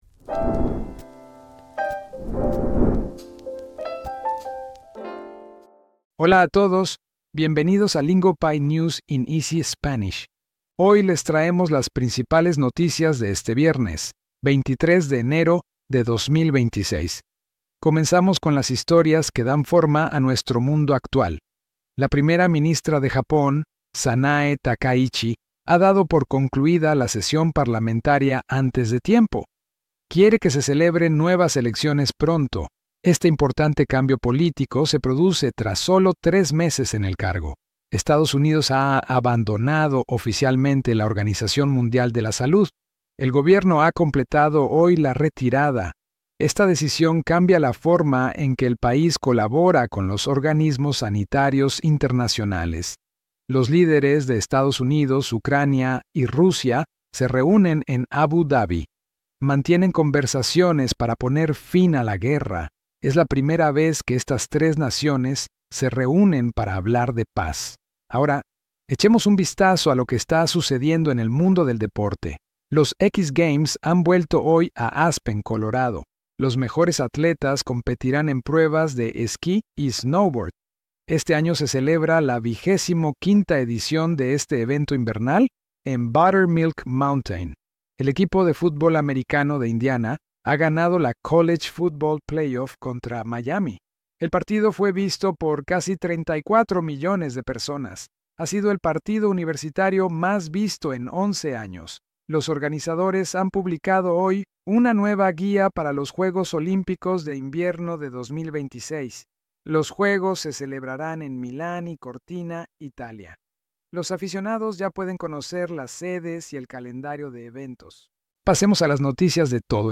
Stay informed and build real listening skills with Lingopie’s News in Easy Spanish, where today’s top world stories are delivered in Spanish you can actually follow.